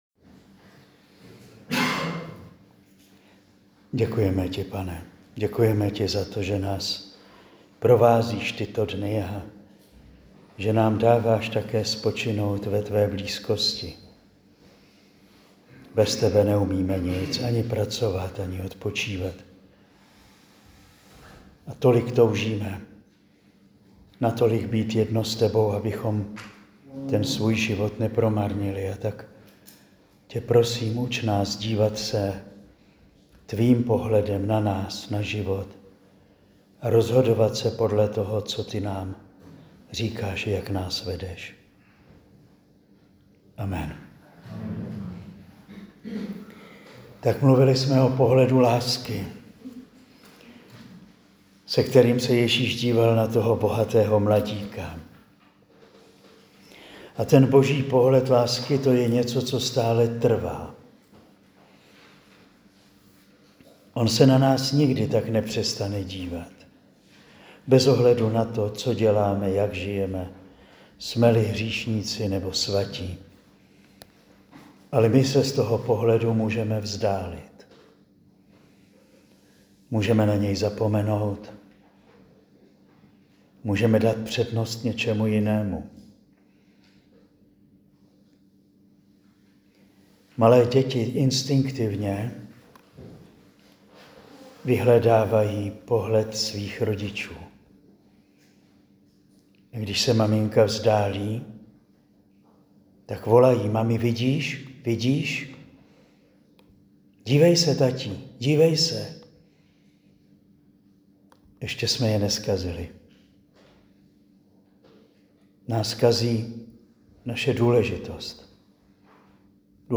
Nyní si můžete poslechnout čtvrtou promluvu.
Promluvy zazněly na exerciciích pro řeckokatolické kněze a jejich manželky v Juskovej Voľi na Slovensku v listopadu 2025.